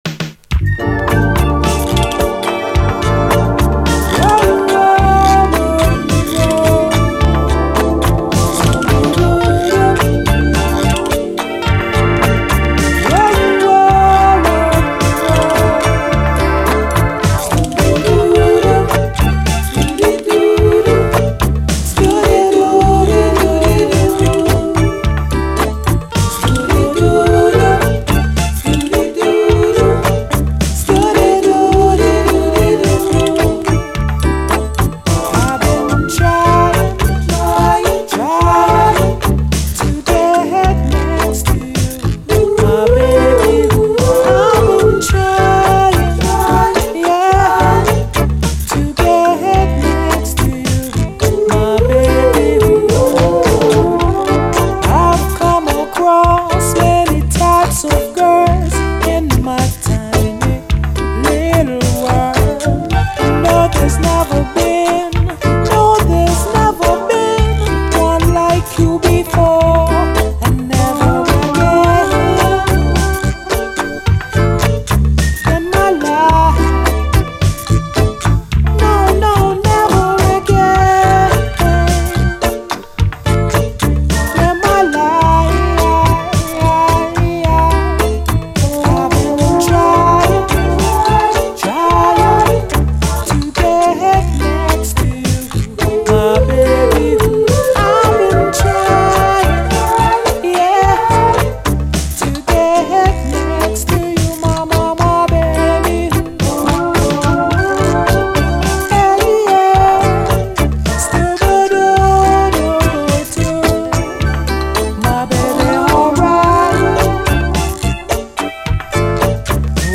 REGGAE
独特なメロウ・バレアリック・レゲエ
• COUNTRY : UK
イントロのバレアリックな響きのシンセからやるせなさが広がる